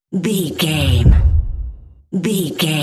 Dramatic hit very deep trailer
Sound Effects
heavy
intense
dark
aggressive
hits